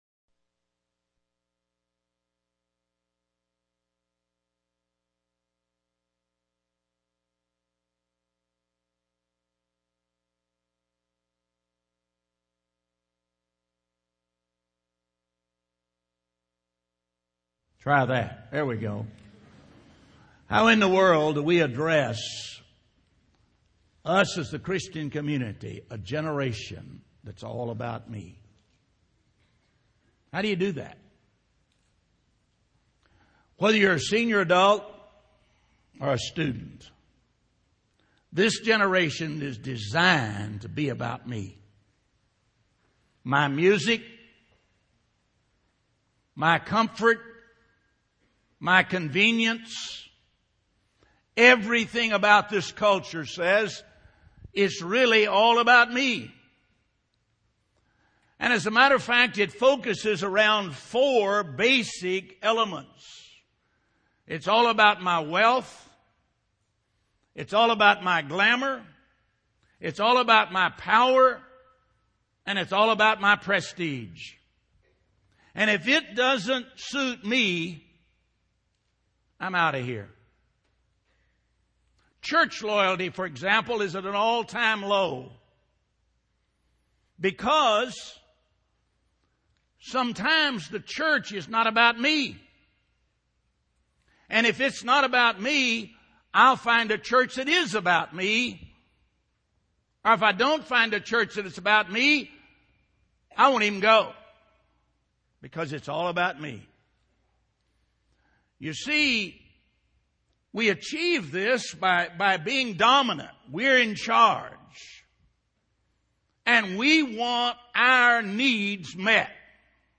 Homecoming Chapel